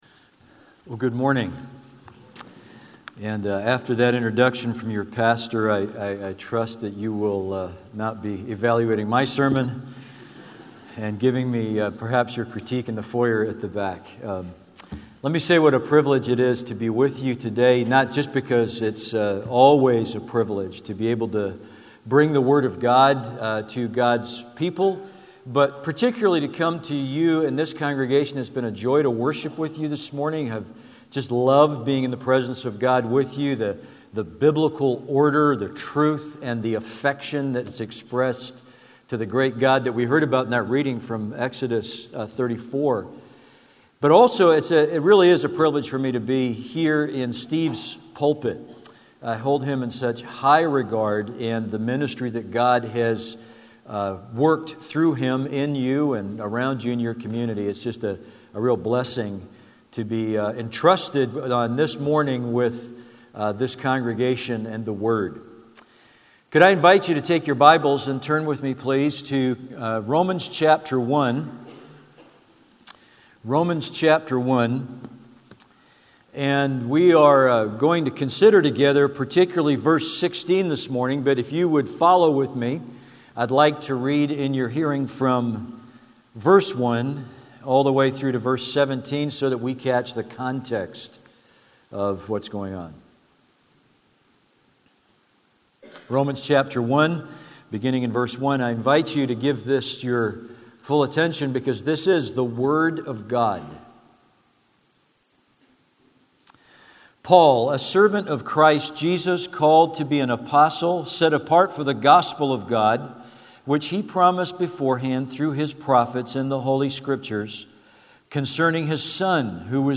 Sermons on Romans 1:16-17 — Audio Sermons — Brick Lane Community Church